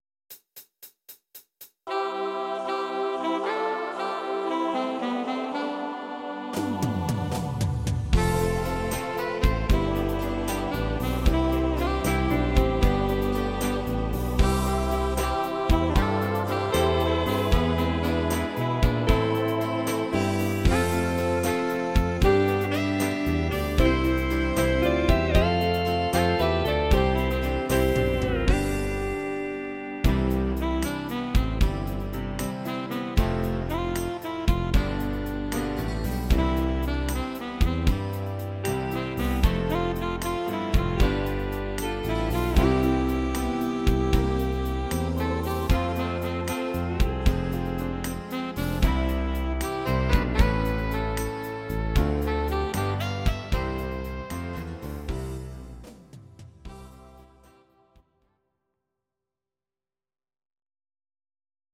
Audio Recordings based on Midi-files
German, Duets, 2000s